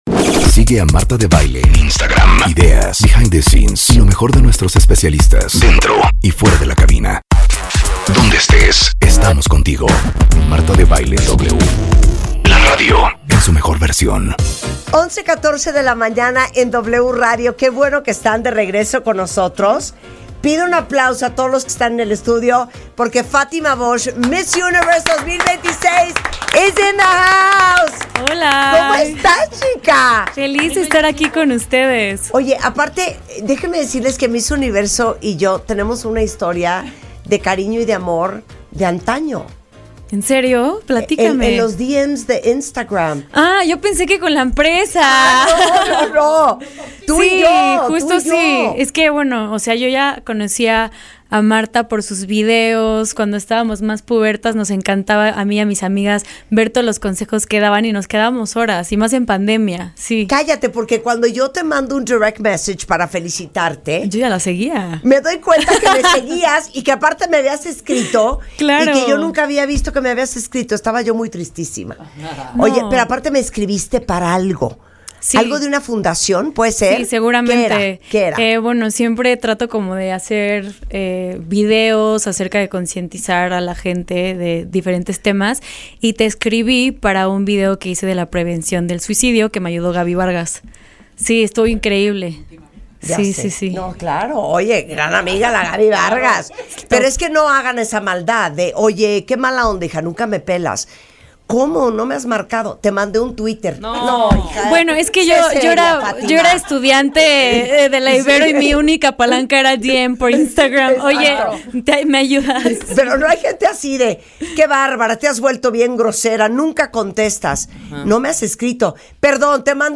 La ganadora del certamen de belleza compartio con la conductora de "W Radio", lo que representa portar la Corona de Miss Universo.